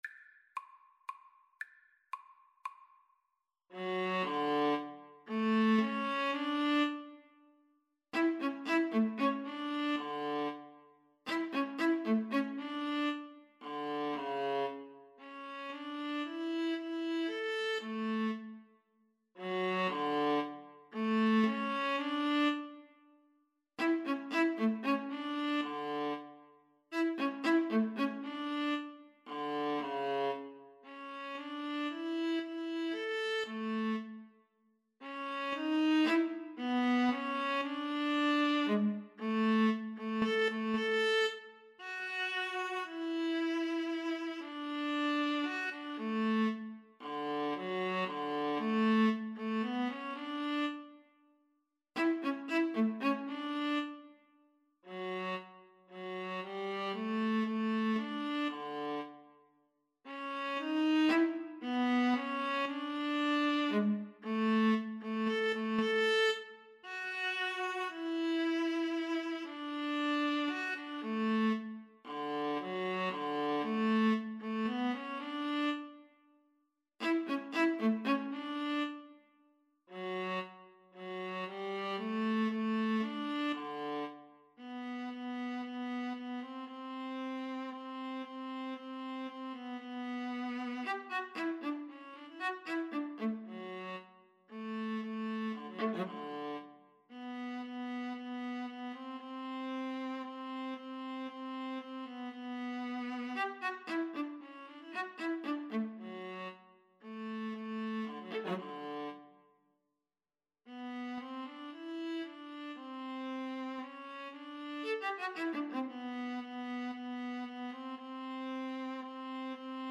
Free Sheet music for Viola Duet
D major (Sounding Pitch) (View more D major Music for Viola Duet )
3/4 (View more 3/4 Music)
Classical (View more Classical Viola Duet Music)